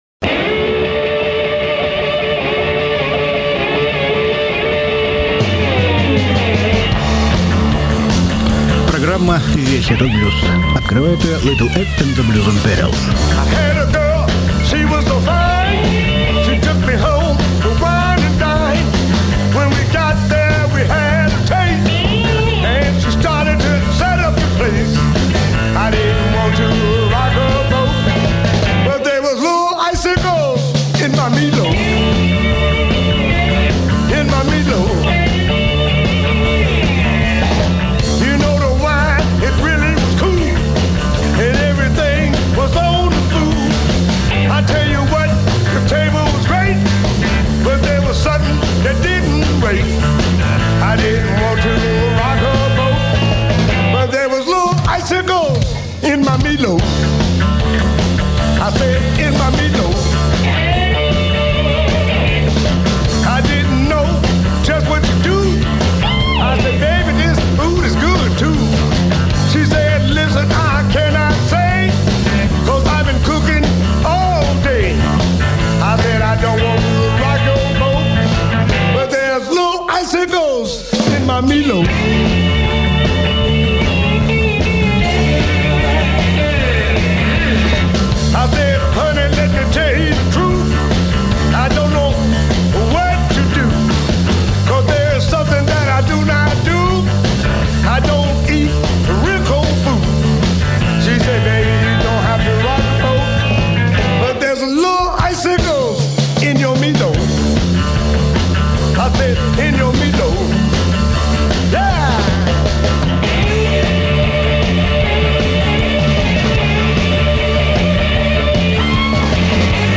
записи слайд-гитары из архива. Часть 2
Слайд-гитара ч.2.mp3